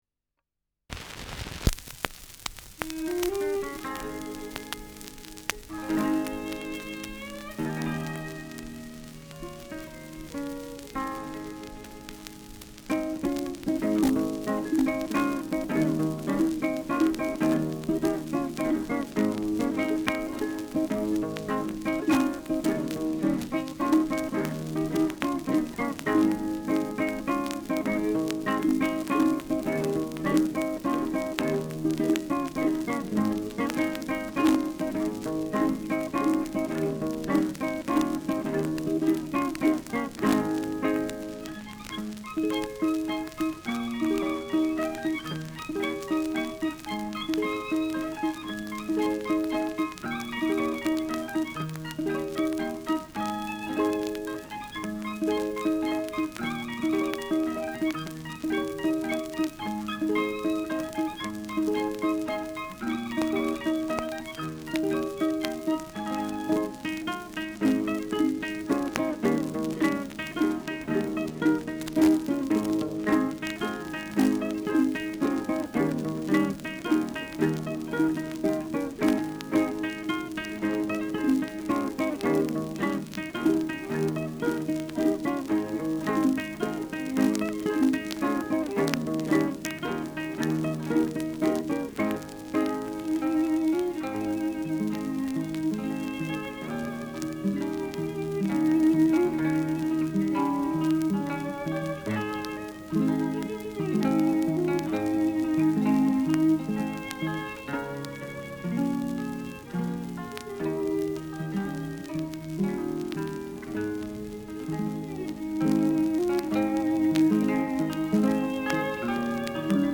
Schellackplatte
Durchgehend leichtes Knistern : Gelegentlich stärkeres bis starkes Knacken und Kratzen : Oszillierender Pfeifton im Hintergrund zum Ende : Leiern
Tegernseer Trio (Interpretation)
[München] (Aufnahmeort)
Stubenmusik* FVS-00016